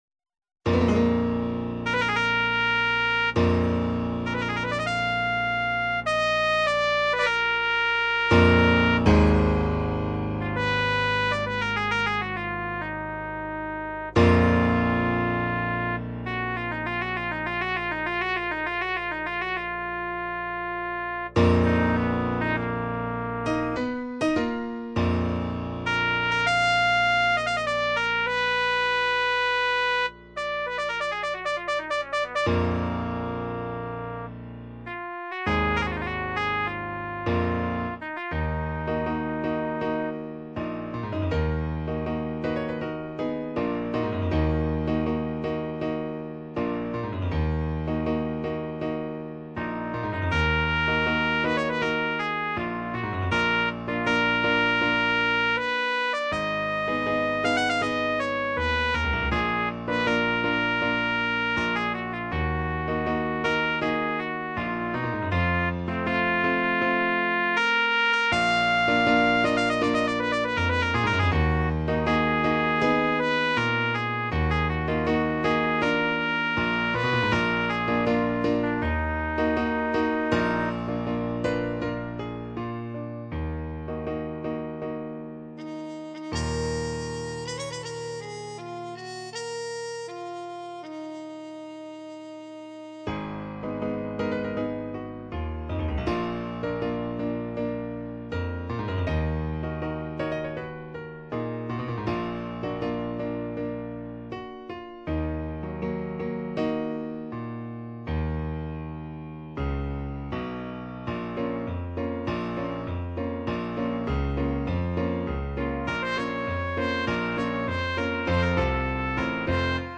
Instrumentalnoten für Trompete